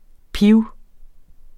Udtale [ ˈpiwˈ- ]